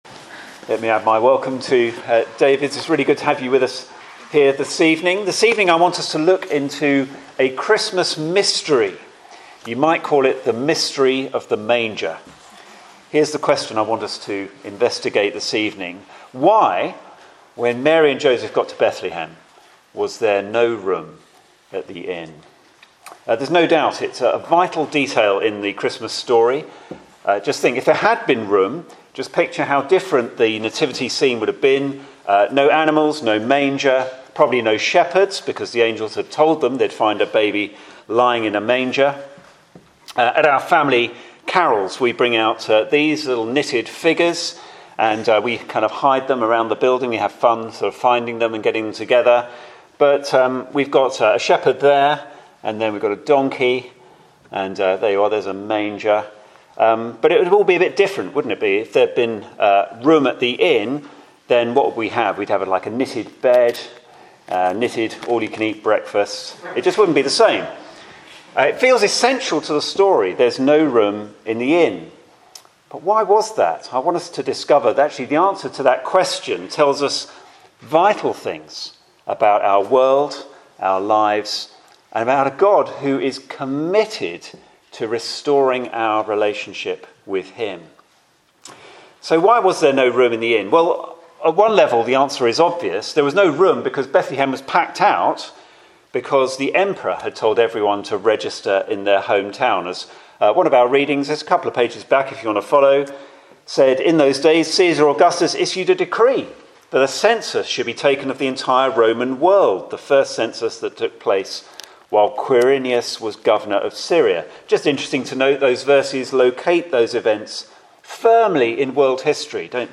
Carols by Candlelight Sermon